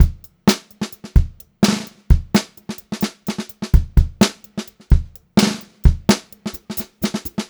128GRBEAT1-L.wav